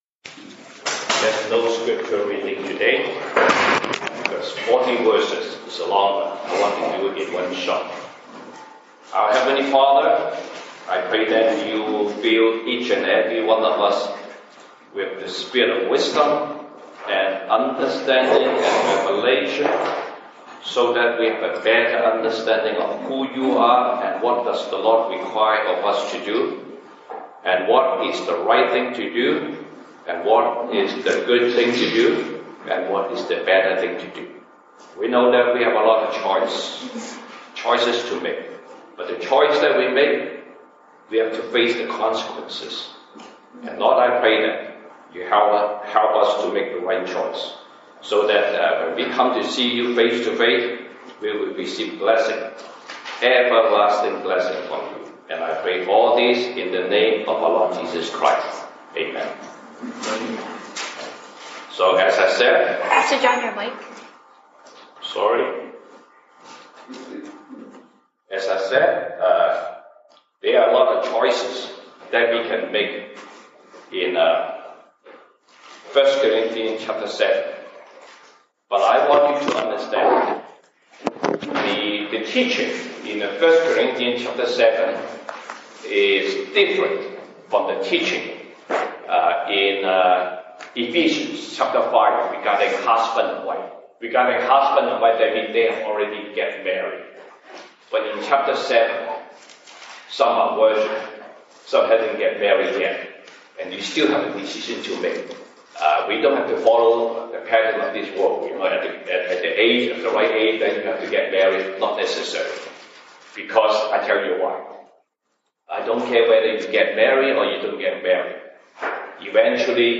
西堂證道 (英語) Sunday Service English: Alright, Good, & Better